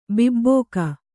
♪ bibbōka